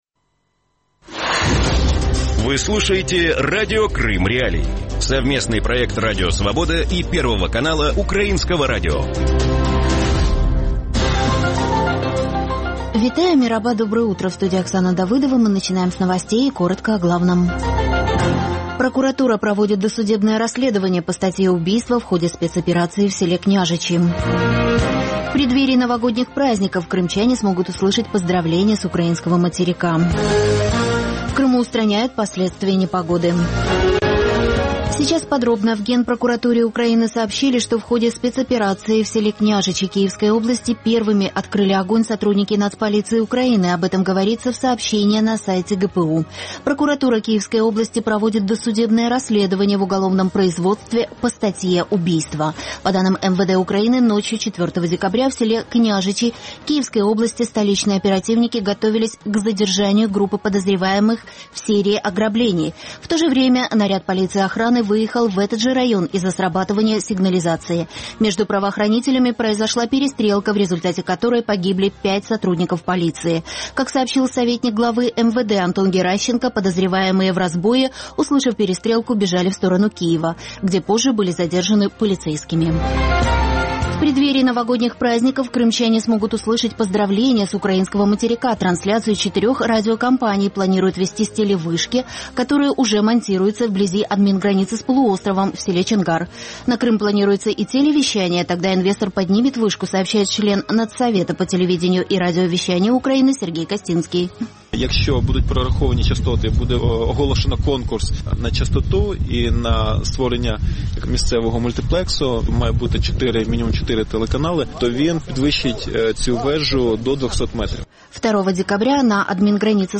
Ранковий ефір новин про події в Криму. Усе найважливіше, що сталося станом на цю годину.